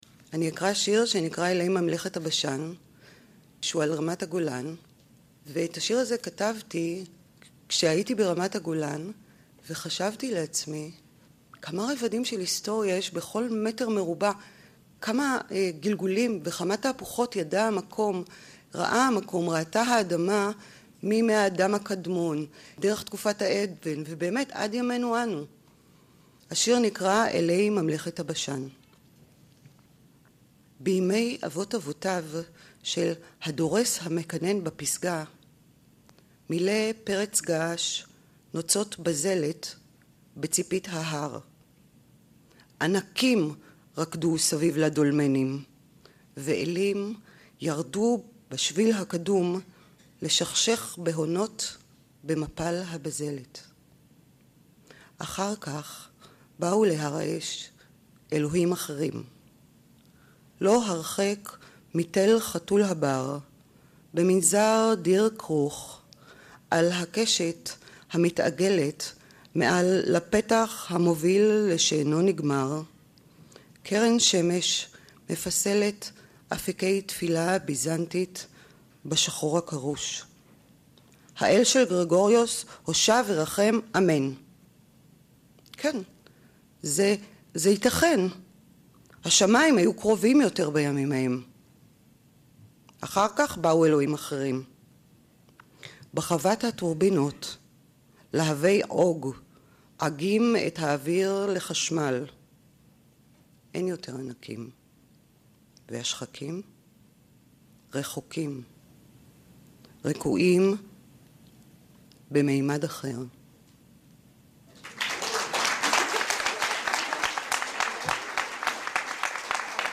הוקראו בפסטיבל המשוררים במטולה, ושודרו ברשת א'